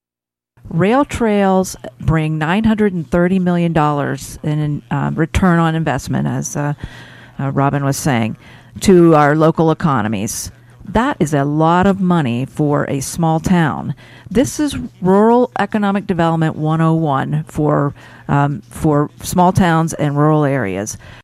This morning on WCCS AM 1160 and 101.1 FM, two debates were held concerning two positions of county government that are on the ballot for next week’s election.